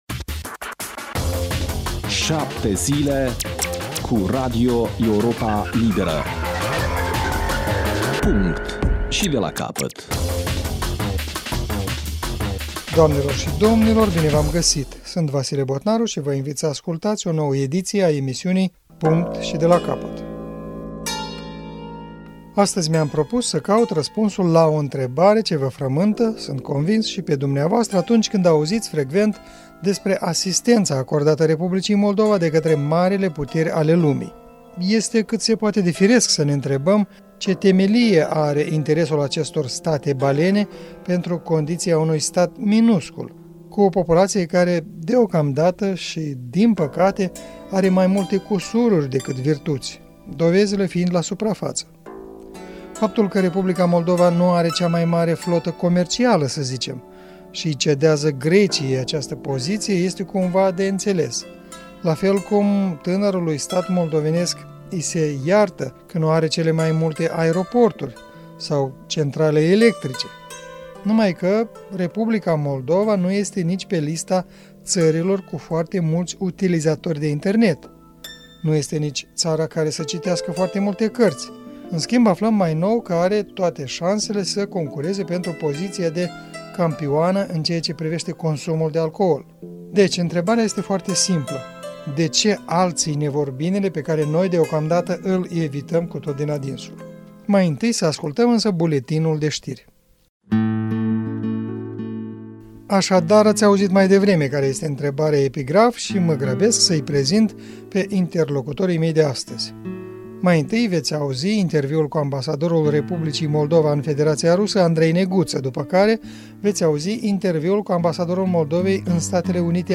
în dialog cu ambasadorii Andrei Neguță și Igor Munteanu